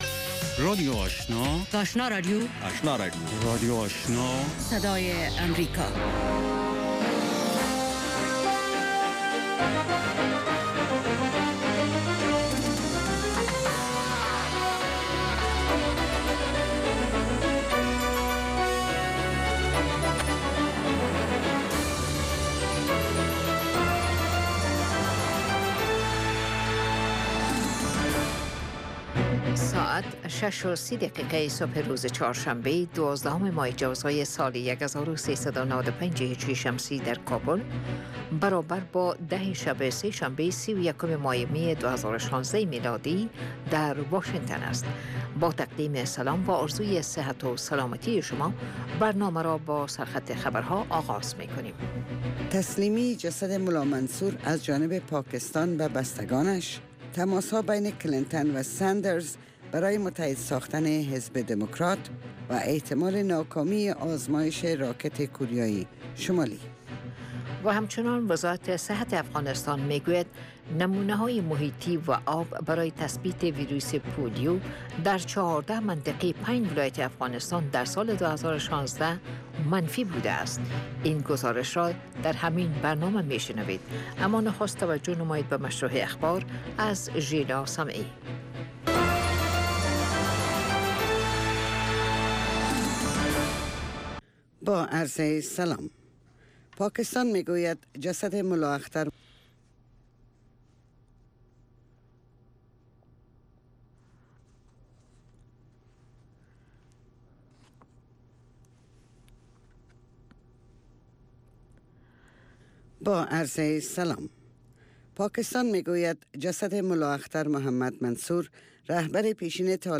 دومین برنامه خبری صبح